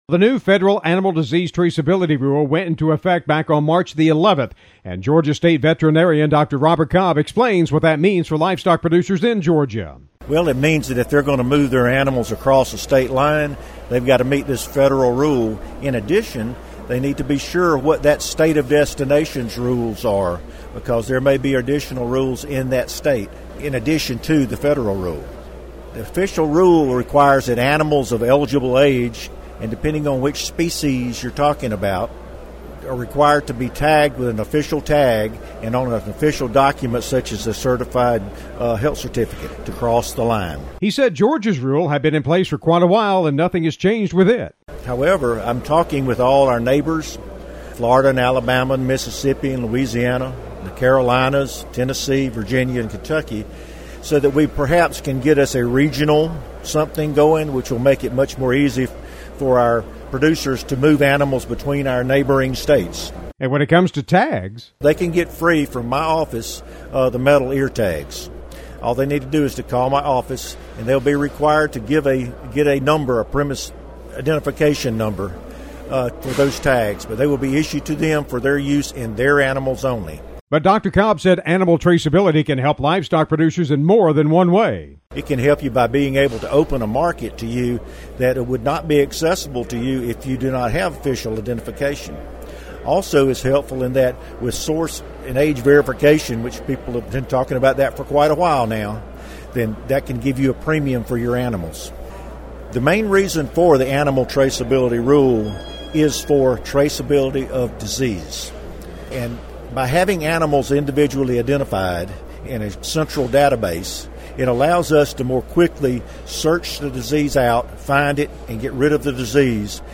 At the recent Georgia Cattlemen’s Assocation Convention, Georgia State Veterinarian Dr. Robert Cobb talked with cattle producers about the new Federal Animal Disease Traceability rule and what it means for them when moving animals not only in state but across state lines.